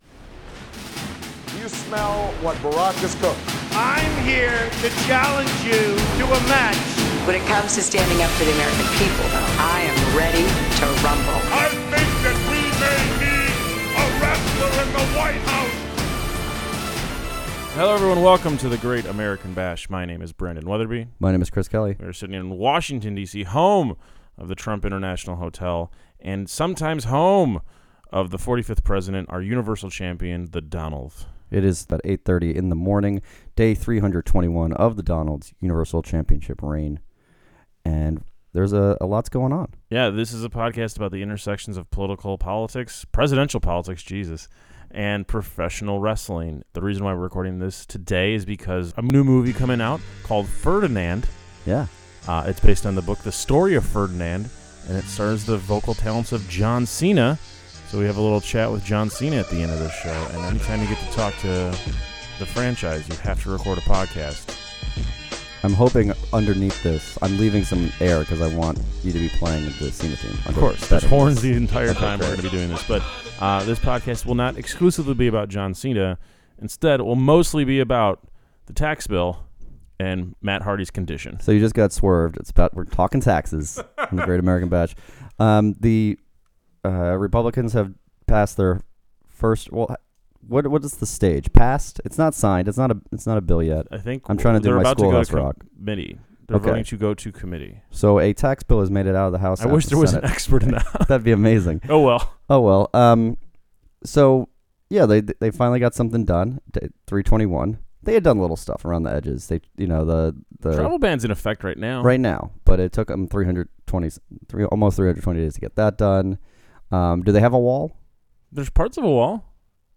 A short chat with The Franchise, John Cena, star of the new children's film "Ferdinand."